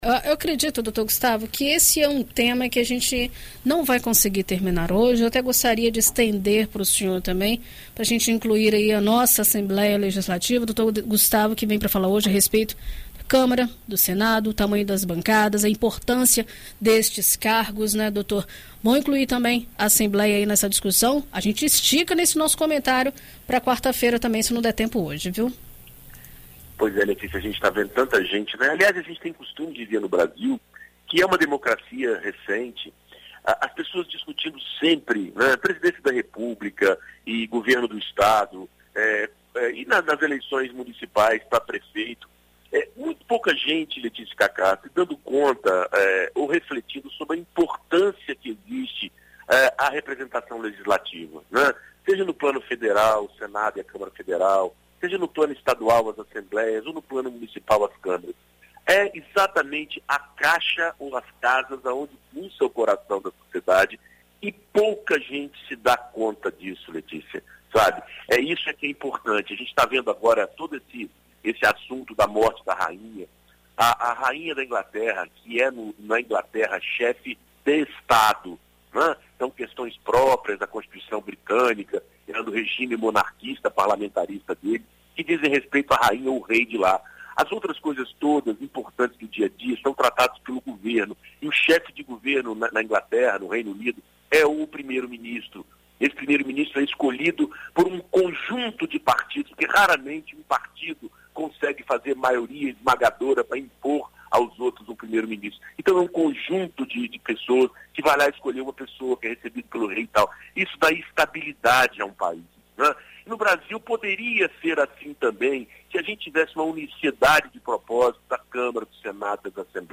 Na coluna Direito para Todos desta segunda-feira (19), na BandNews FM Espírito Santo